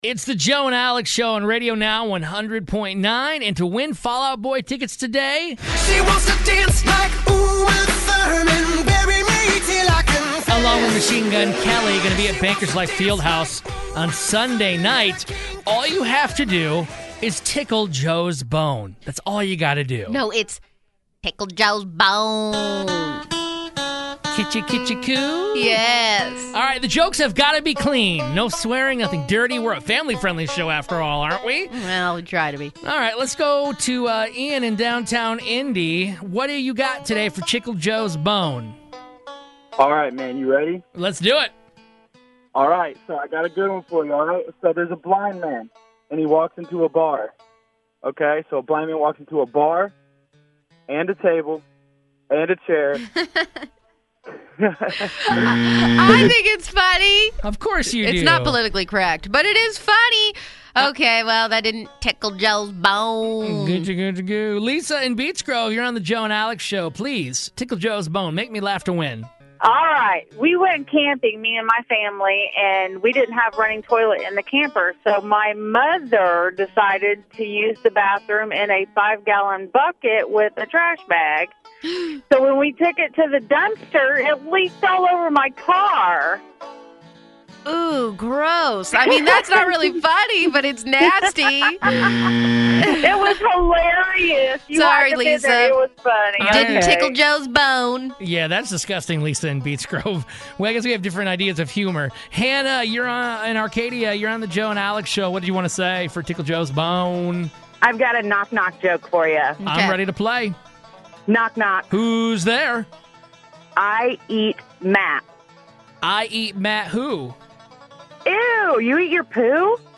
But we have listeners call in and take on the challenge of making him laugh in order to win Fall Out Boy tickets.